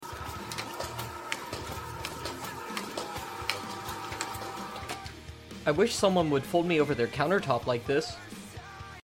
Automated paper folder.